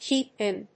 アクセントkèep ín